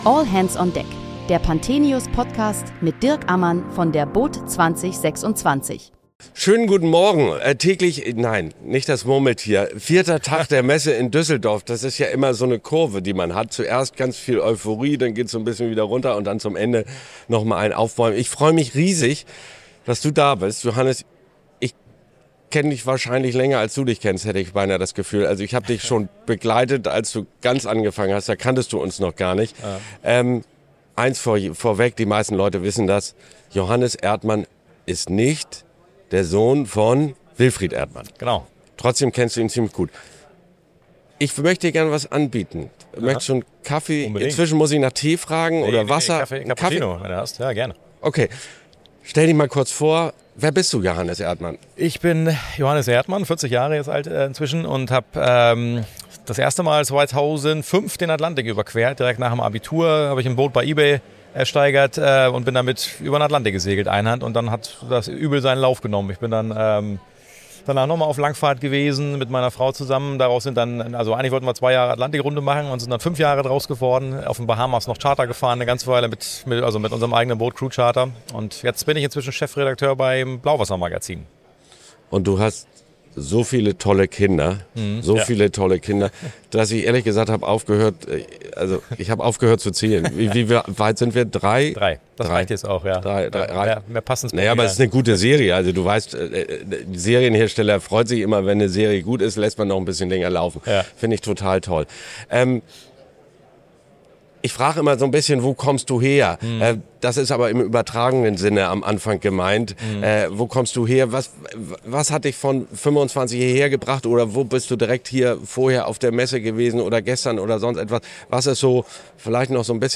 Während der boot 2026 sprechen wir täglich mit Gästen aus der Branche über aktuelle und kontroverse Themen rund um den Wassersport.